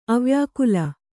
♪ avyākula